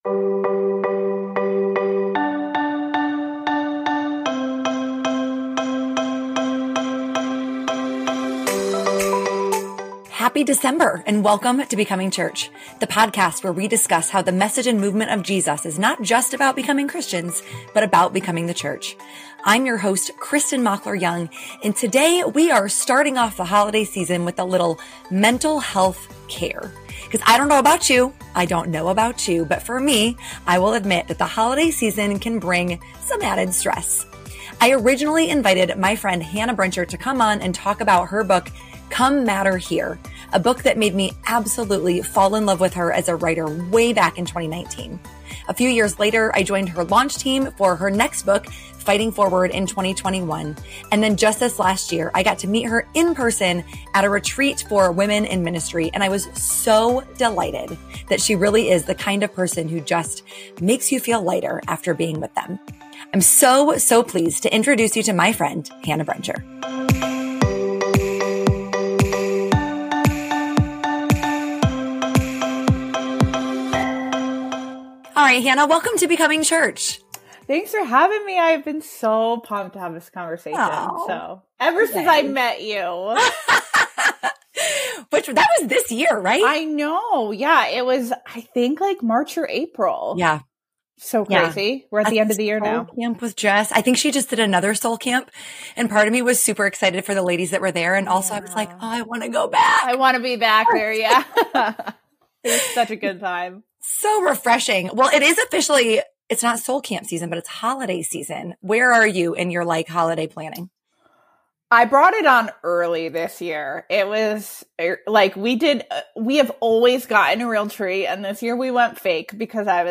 There is mention of depression and suicidal ideation in this conversation but there is also hope as we talk about how the church can approach mental health differently.